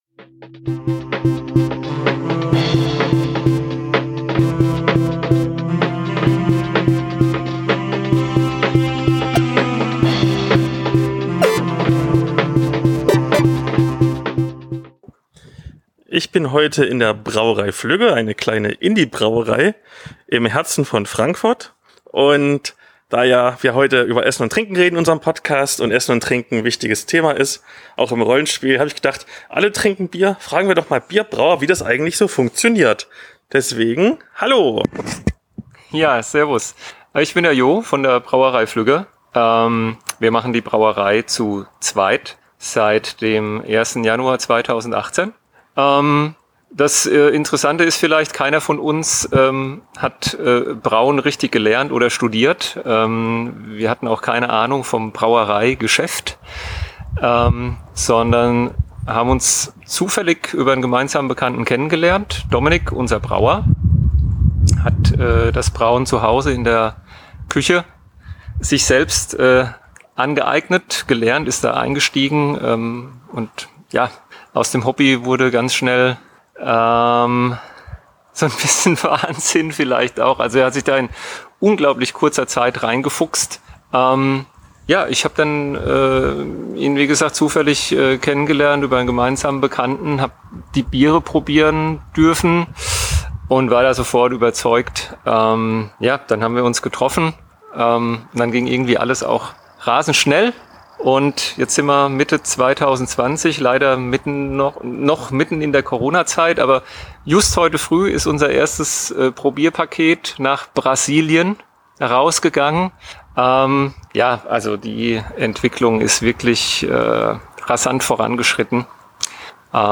In der regulären Trashtalk-Folge gab es bereits die stark gekürzte Version, in dieser Bonusfolge kommt nun die ungeschnittene Fassung.